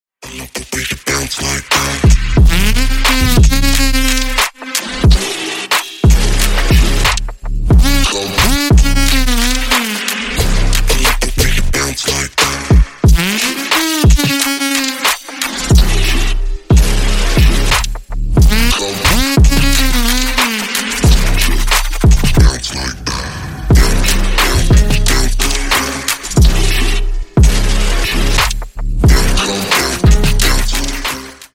Громкие Рингтоны С Басами
Рингтоны Электроника